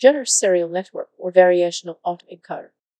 coqui-tts - a deep learning toolkit for Text-to-Speech, battle-tested in research and production